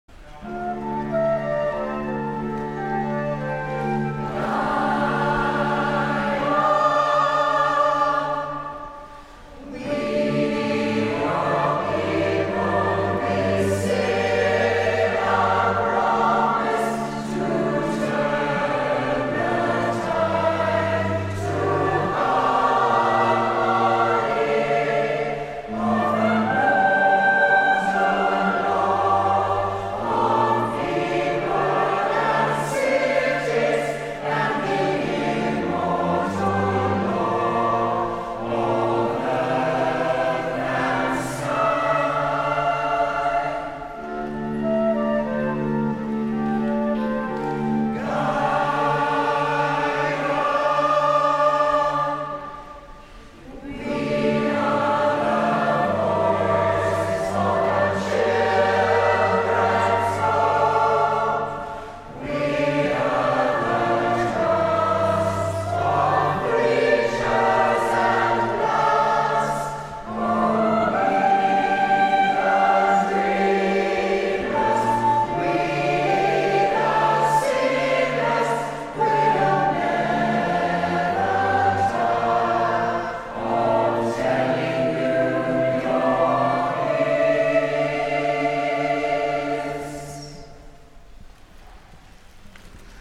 This recording was made at our 20th anniversary singing, when we returned to the Foyer of the Australian Parliament House in 2023 (click on the image to hear the music).
The Promise - Peoples Chorus in APH 2023.mp3